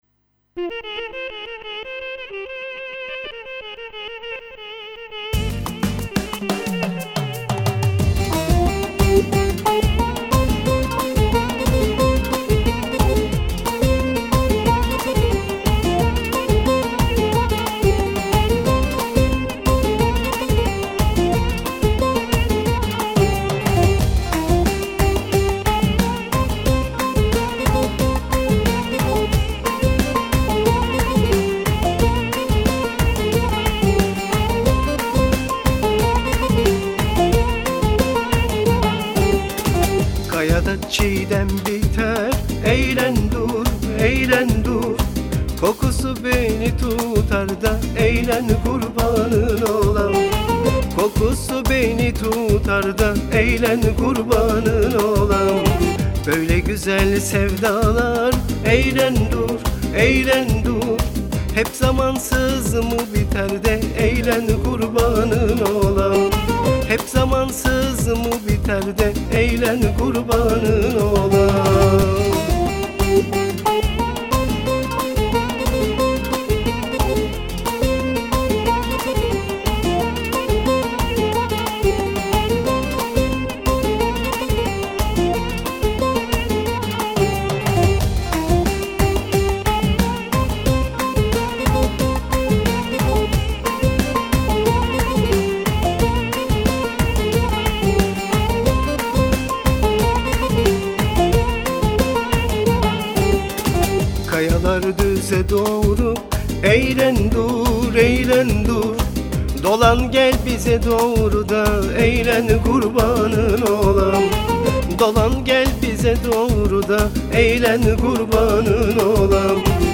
Halk Müziği
hareketli tarzda